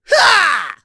Ezekiel-Vox_Attack2.wav